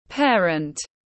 Bố mẹ tiếng anh gọi là parent, phiên âm tiếng anh đọc là /ˈpeə.rənt/.
Parent /ˈpeə.rənt/